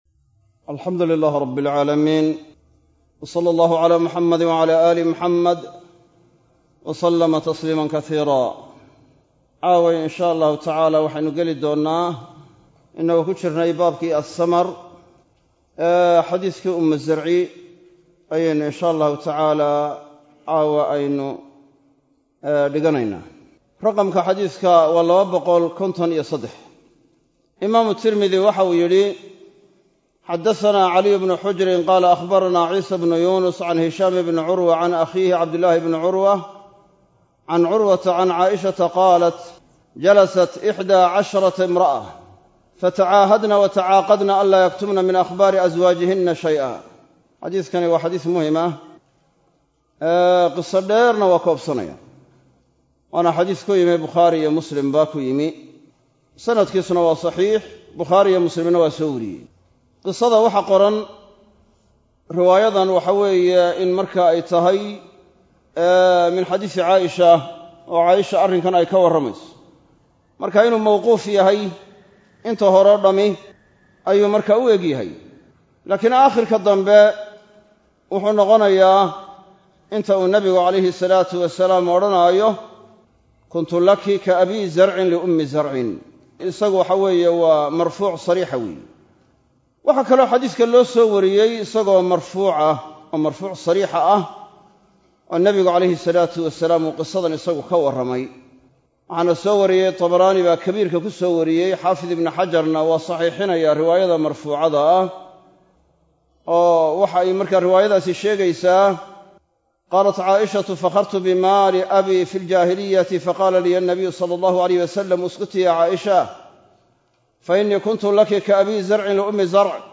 Masjid Af-Gooye – Burco